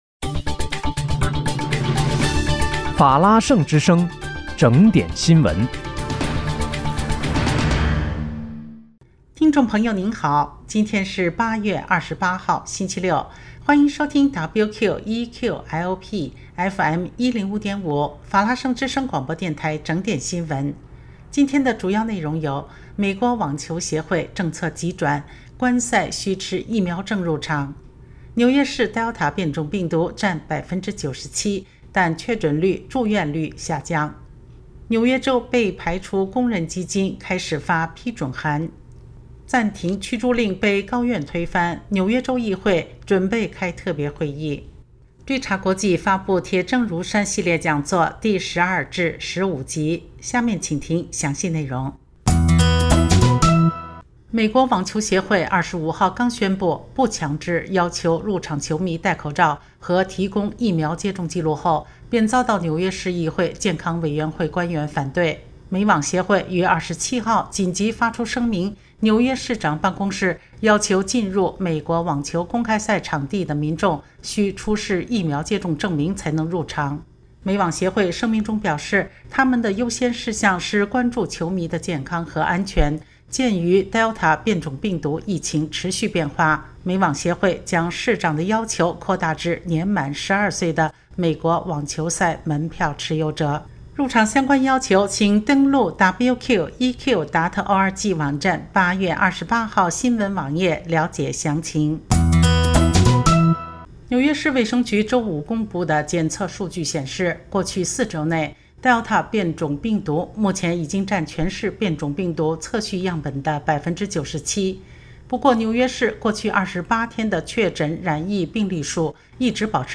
8月28日（星期六）纽约整点新闻